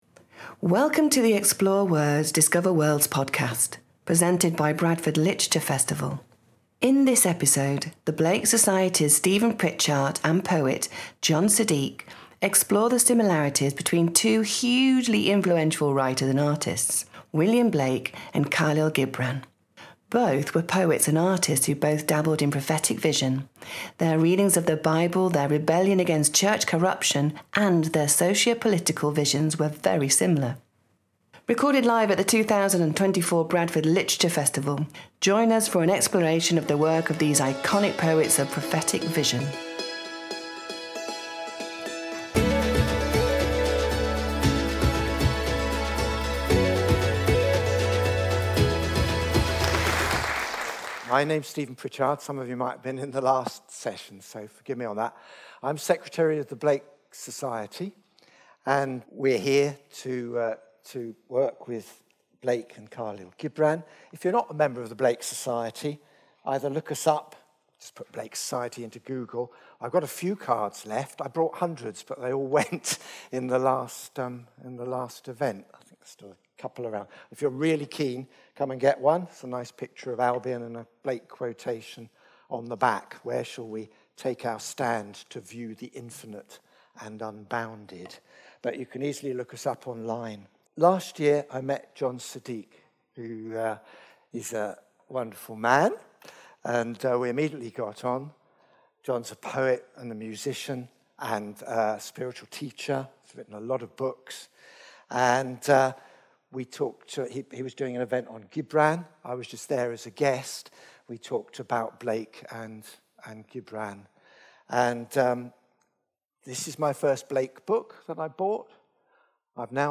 A unique conversation